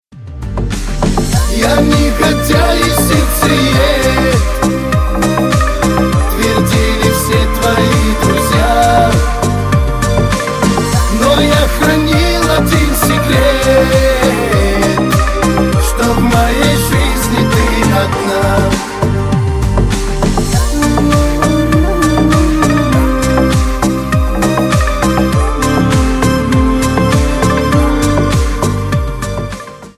Кавказские Рингтоны
Шансон Рингтоны